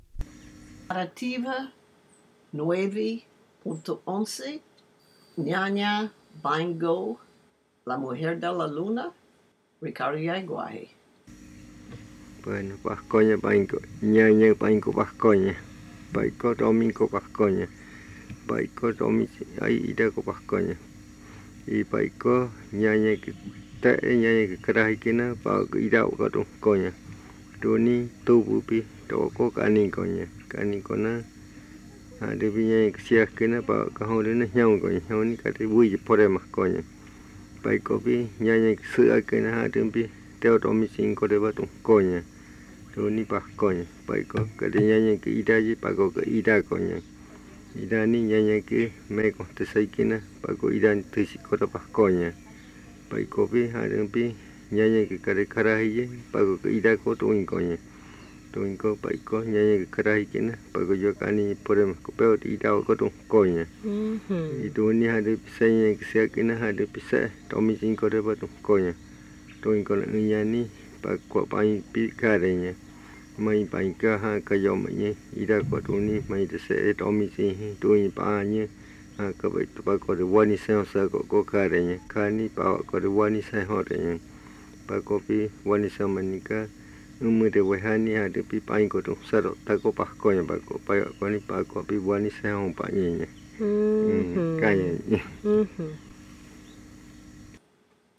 Buenavista, río Putumayo (Colombia)
Una narración sobre una mujer que era joven con la luna nueva y envejeció con la luna menguante. Se casó con un cristiano y fue asesinada cuando quedó embarazada.